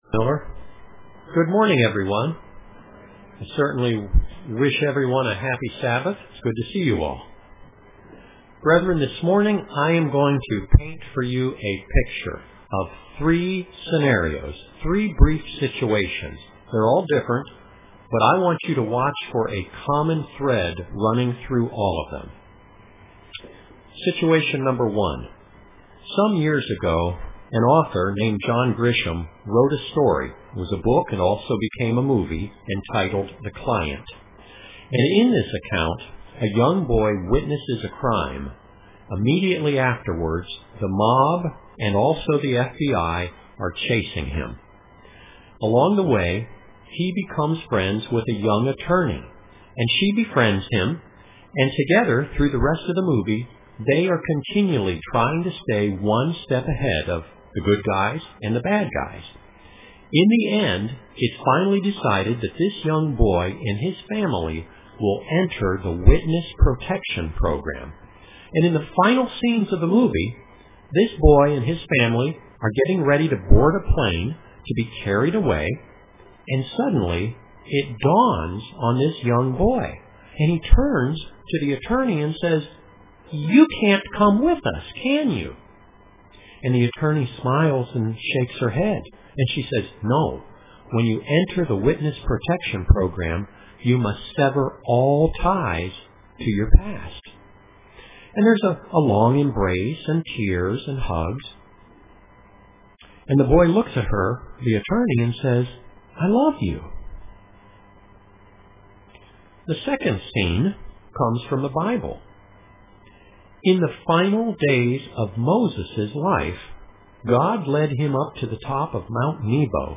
Print Remember Those Left Behind UCG Sermon Studying the bible?